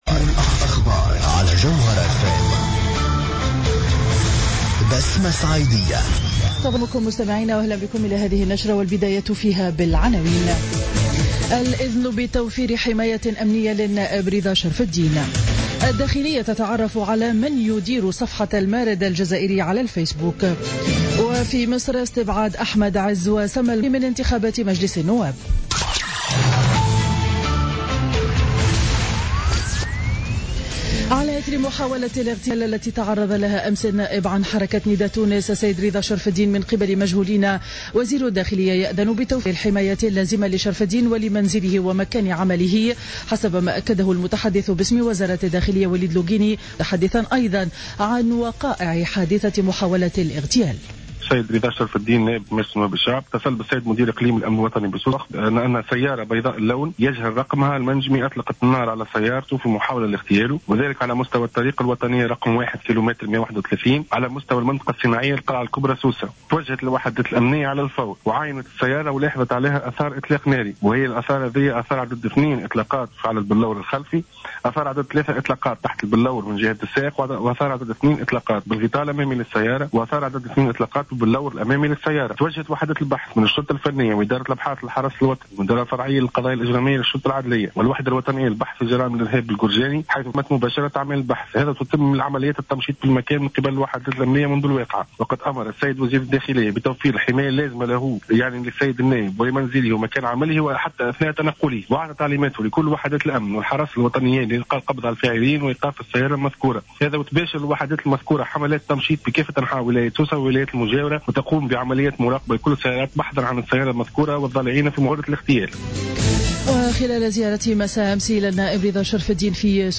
نشرة أخبار السابعة صباحا ليوم الجمعة 9 أكتوبر 2015